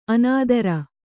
♪ anādara